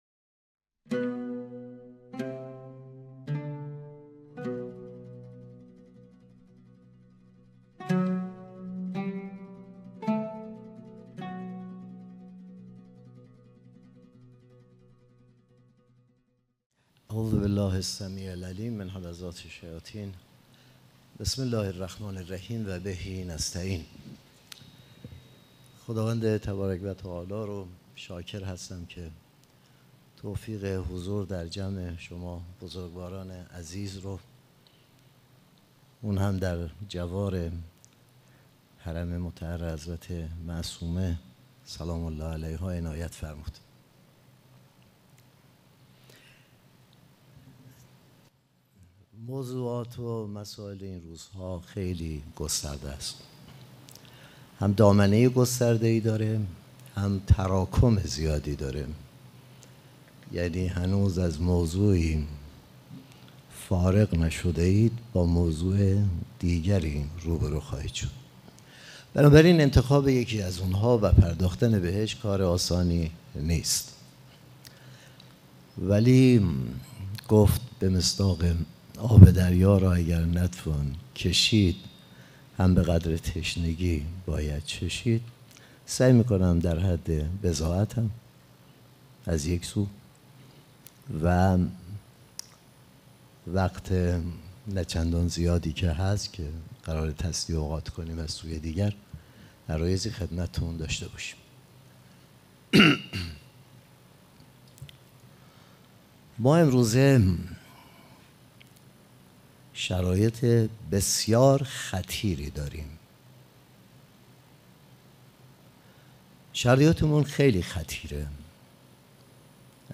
سخنرانی
سومین اجلاس ملی رابطان جامعه ایمانی مشعر